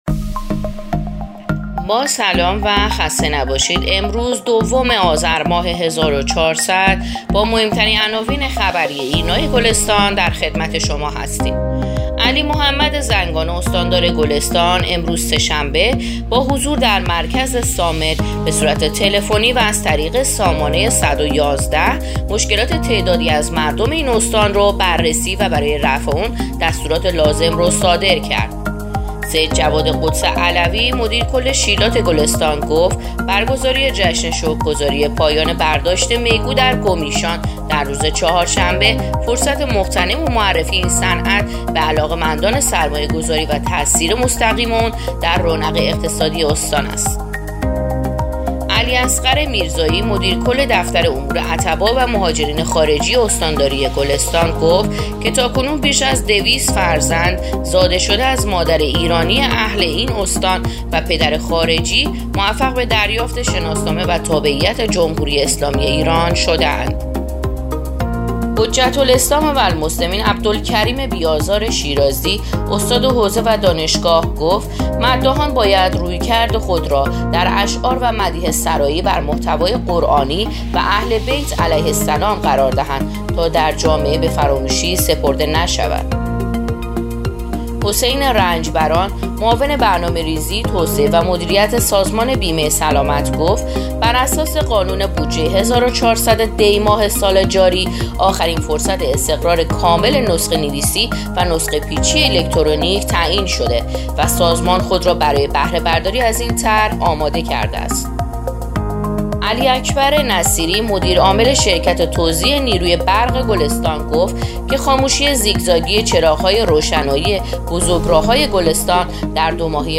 پادکست/اخبار شبانگاهی دوم آذر ایرنا گلستان